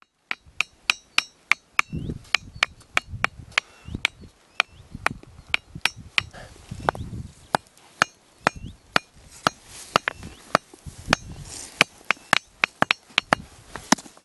Type: Ringing stone
The pitch is high, with slightly lower frequencies on the sides and the highest on top.
NB: The sound quality of the recordings (from 2007) unfortunately does not do justice to the singing stone. The recordings sound best with headphones.